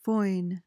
PRONUNCIATION: (foin) MEANING: verb intr.: To thrust with a weapon; lunge. noun: A thrust with a weapon.